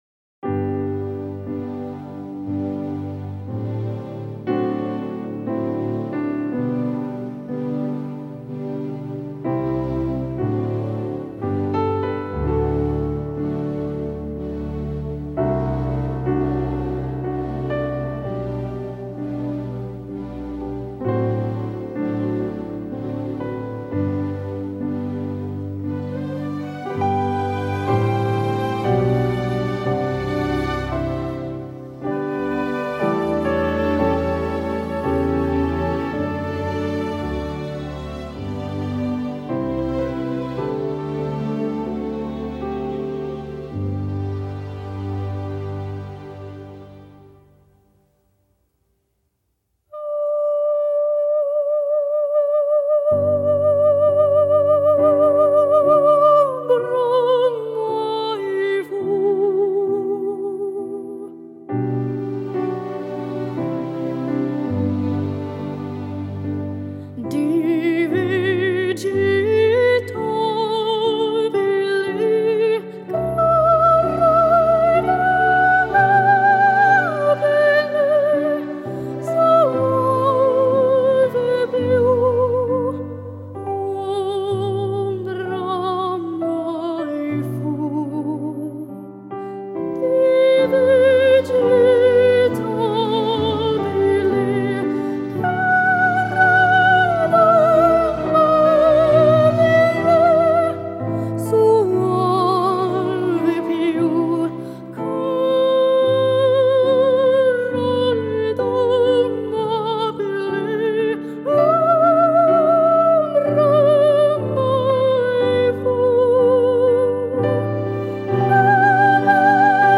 ★ 種類涵蓋爵士、古典、流行、民謠等不同曲風，以最優異的設備、最發燒的手法精心錄製！